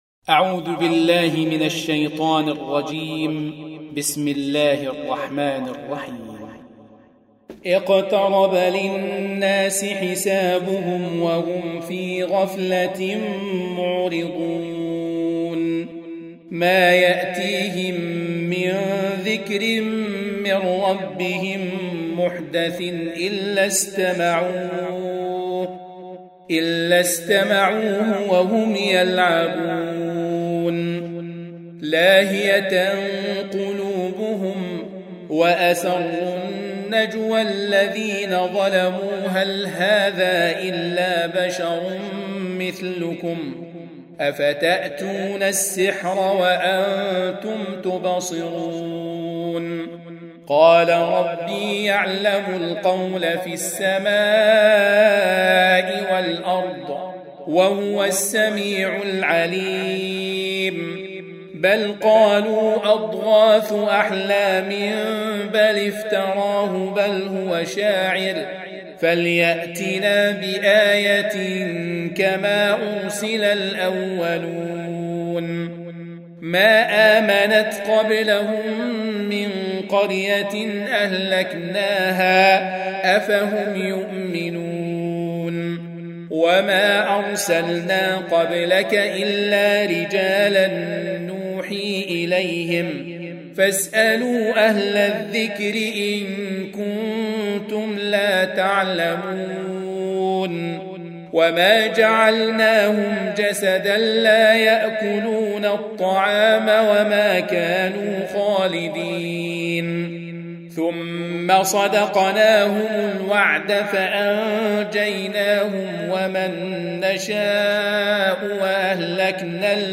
Audio Quran Tarteel Recitation
حفص عن عاصم Hafs for Assem
Surah Sequence تتابع السورة Download Surah حمّل السورة Reciting Murattalah Audio for 21. Surah Al-Anbiy�' سورة الأنبياء N.B *Surah Includes Al-Basmalah Reciters Sequents تتابع التلاوات Reciters Repeats تكرار التلاوات